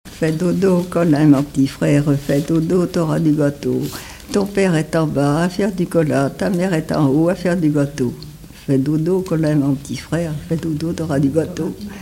berceuse
Pièce musicale inédite